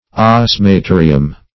osmaterium - definition of osmaterium - synonyms, pronunciation, spelling from Free Dictionary
Search Result for " osmaterium" : The Collaborative International Dictionary of English v.0.48: Osmaterium \Os`ma*te"ri*um\, n.; pl.